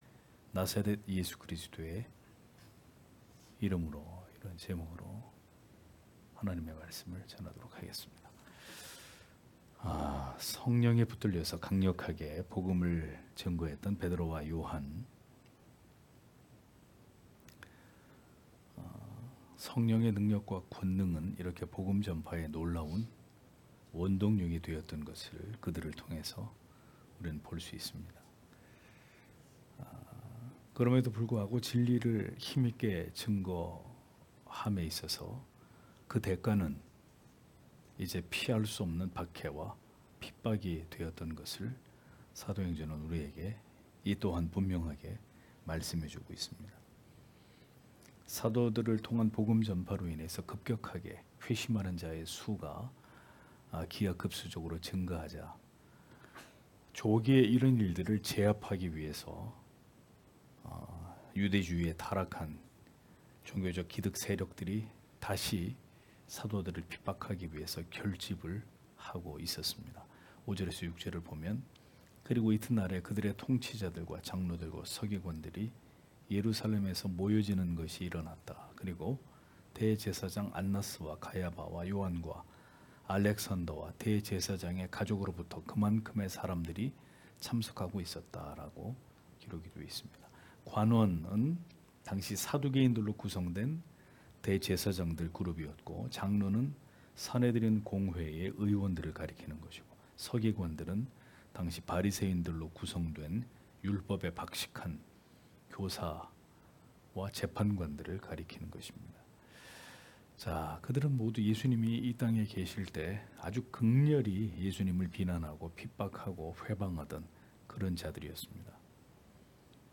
금요기도회 - [사도행전 강해 25] 나사렛 예수 그리스도의 이름으로 (행 4장 5-10절)